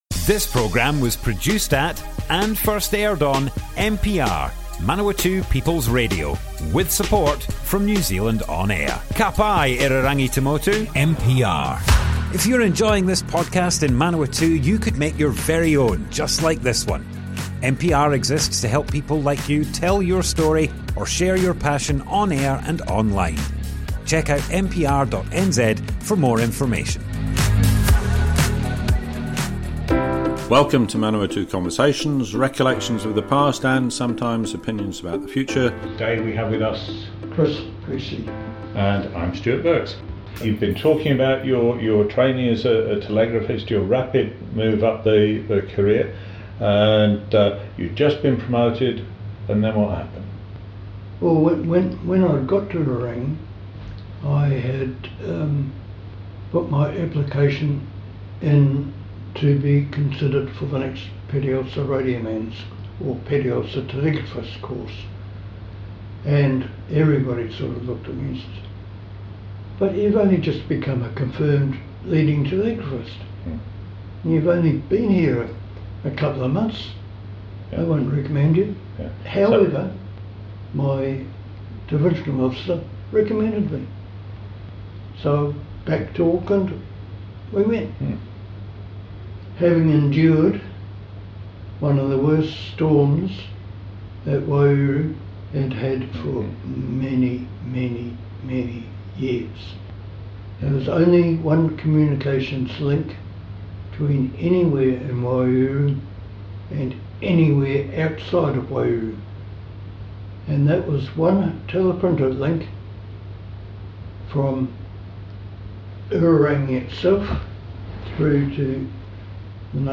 Manawatu Conversations More Info → Description Broadcast on Manawatu People's Radio, 1st July 2025.
oral history